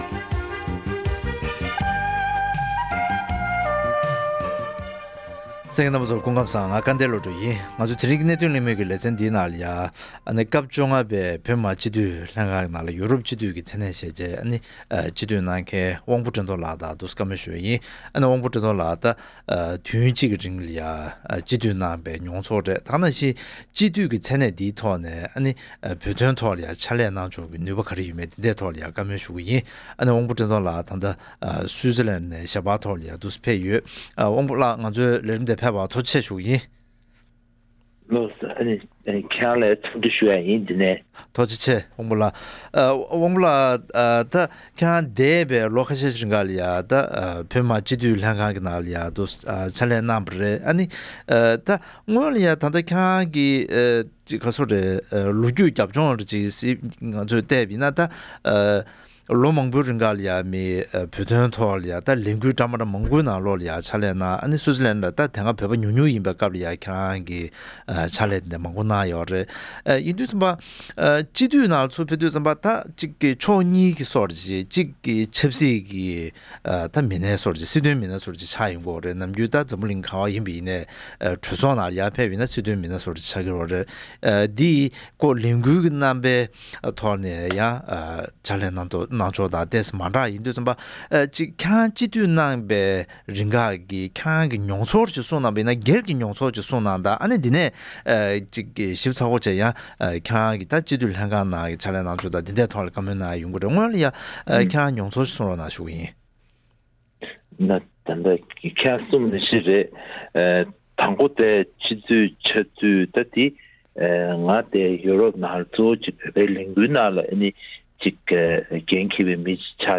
༄༅། །ཐེངས་འདིའི་གནད་དོན་གླེང་མོལ་གྱི་ལེ་ཚན་ནང་དུ།